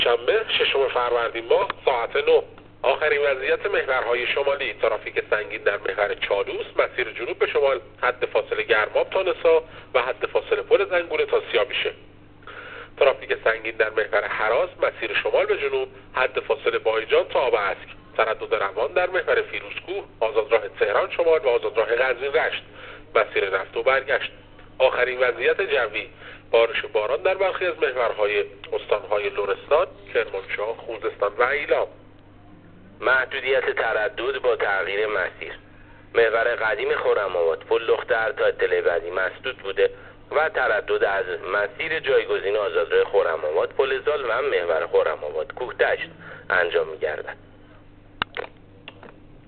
گزارش رادیو اینترنتی از آخرین وضعیت ترافیکی جاده‌ها تا ساعت۹ ششم فروردین ماه؛